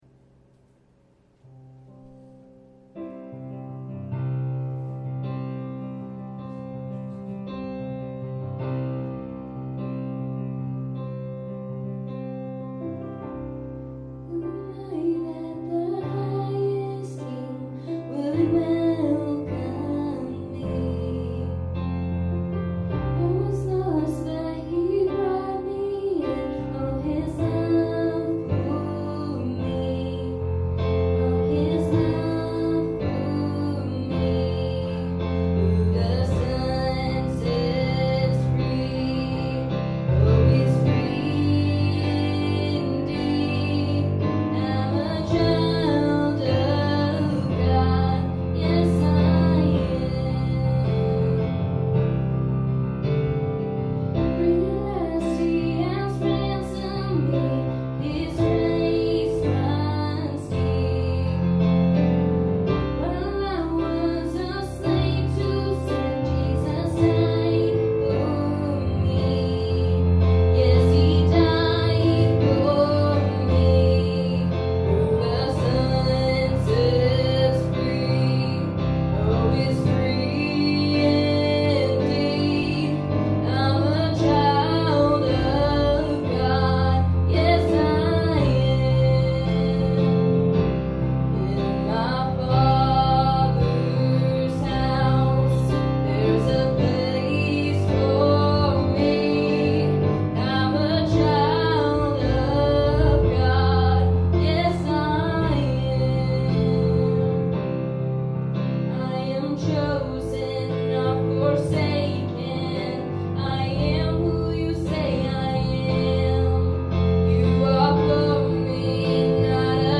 2020 Sermon Audio Archive